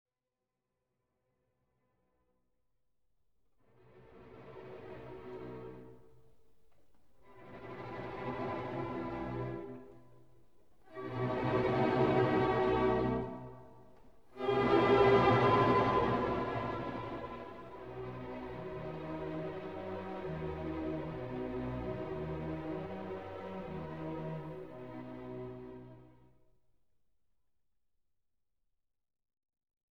Classical and Opera